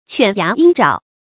犬牙鷹爪 注音： ㄑㄨㄢˇ ㄧㄚˊ ㄧㄥ ㄓㄠˇ 讀音讀法： 意思解釋： 喻善攫取的手段。